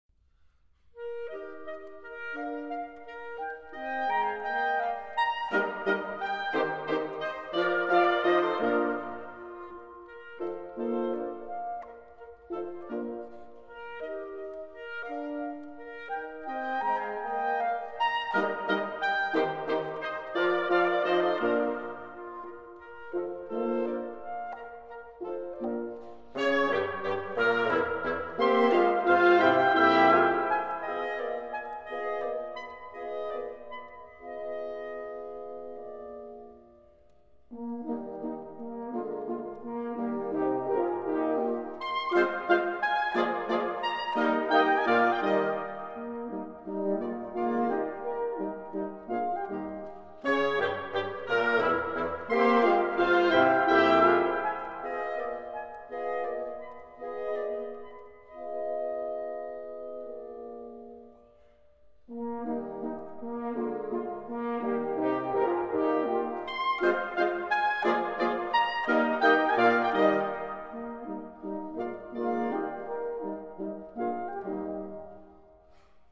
古典类型: 室内乐
录音制式:DDD